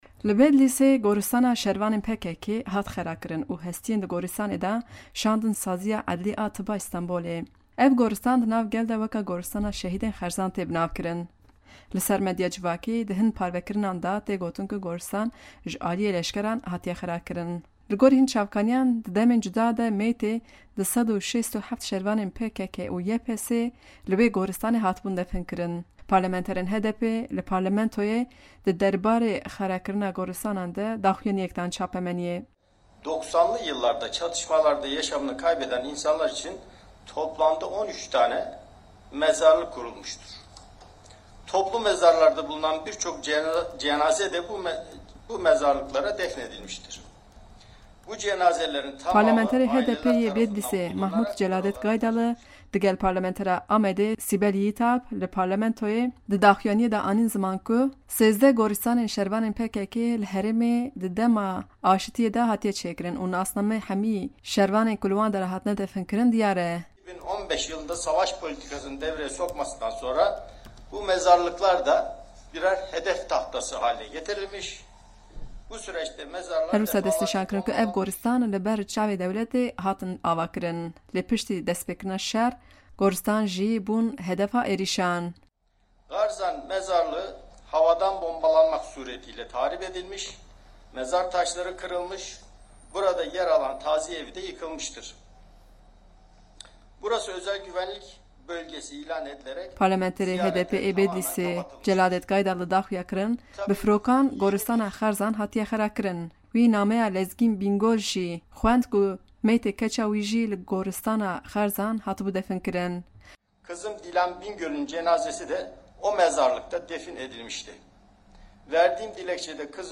Parlamenterê HDPê yê Bedlisê Mahmud Celadet Gaydali digel parlamentera Amedê Sibel Yigitalp li parlamentoyê derbarê vê bûyerê de daxuyaniyek dan çapameniyê.